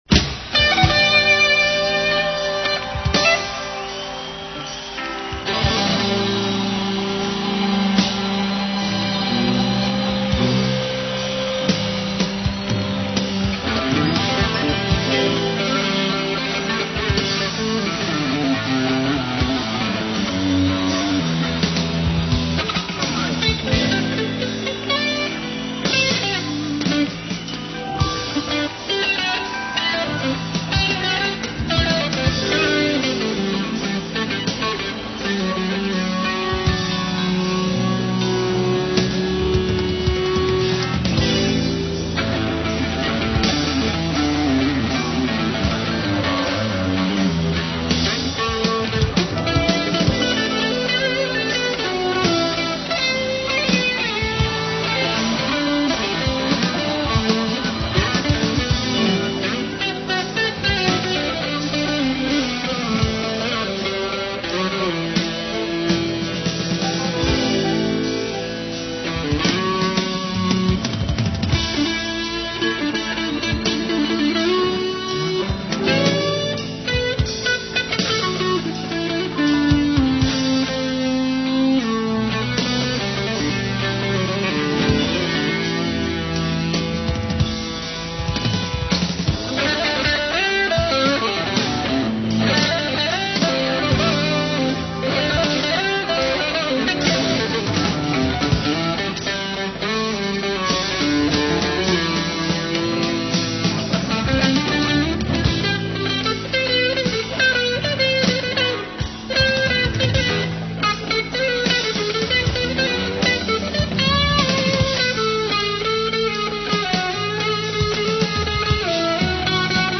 Recorded live at the NYC Palladium, October 31, 1978.